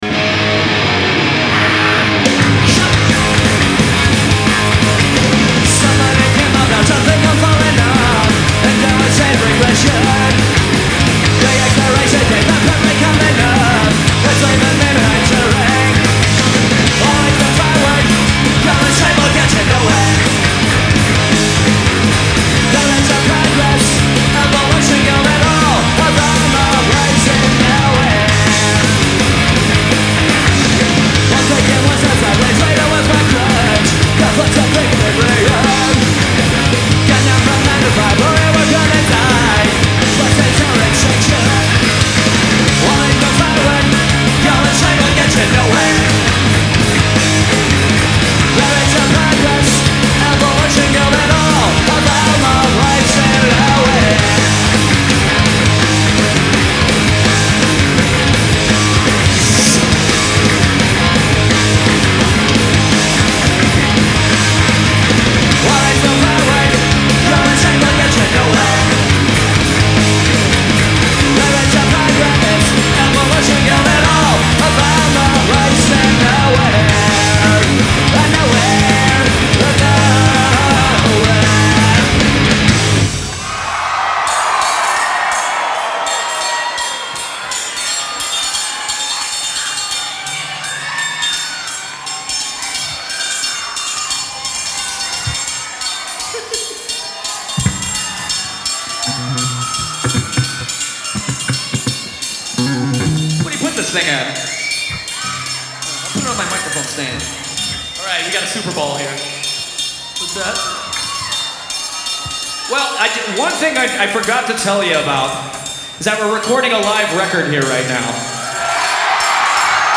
Live at the Electric Factory, Philadelphia 11/14/97